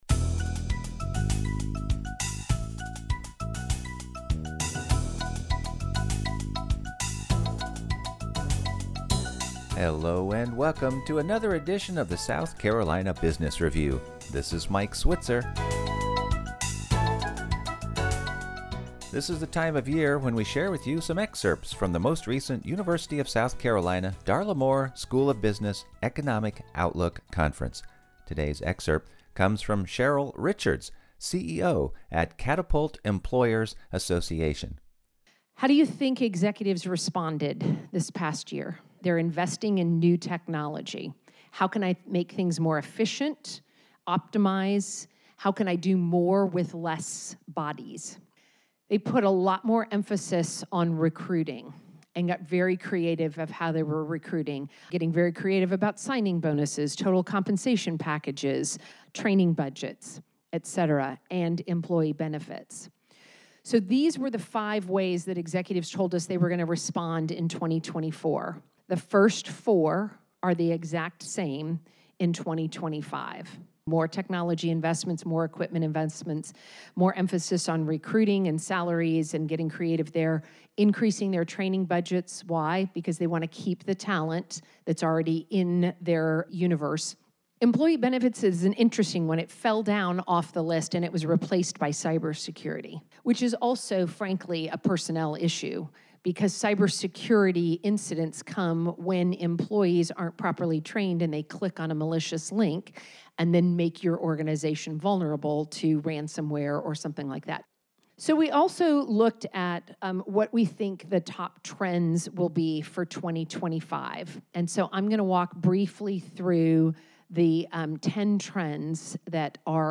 focuses on news from South Carolina's business community with interviews of many small business owners and business leaders from around the state. South Carolina's nonprofits, including its colleges and universities are also regularly featured on the program, as well as many of the state's small business support organizations.